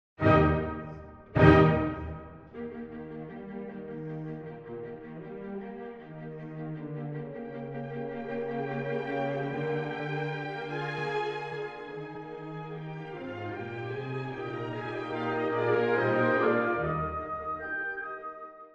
↑古い録音のため聴きづらいかもしれません！（以下同様）
Allegro con brio
～快速に、活気をもって～
テーマはチェロから。優雅で伸びやかに歌われます。
ほかにも、ホルンが朗々と歌うさまなど、すでに後期ロマン派を思わせるような作風が素敵ですね。
また、弱拍や裏拍からリズムを作ることで、音楽に推進力を持たせています。シンコペーションなどが積極的に使われています。